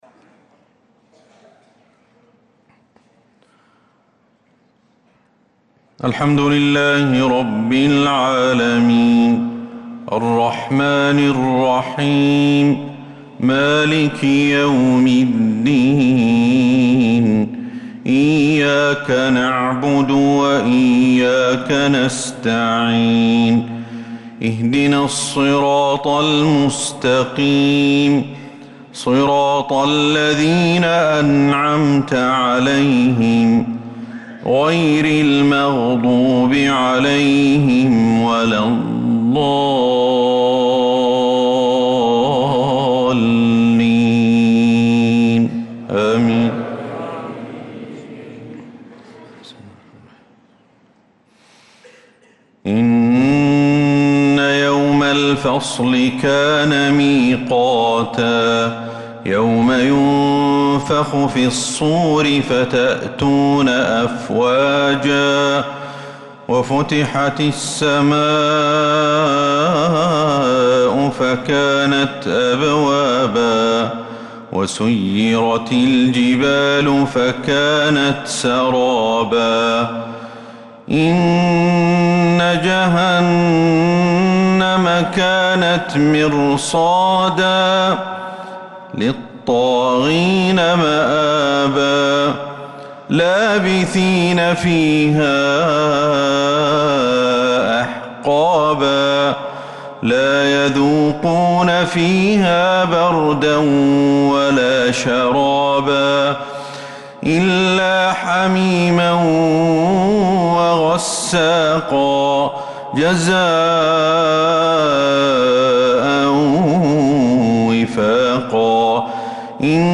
مغرب الخميس 2-7-1446هـ خواتيم سورة النبأ 17-40 | Maghrib prayer from Surat An-Naba 2-1-2025 > 1446 🕌 > الفروض - تلاوات الحرمين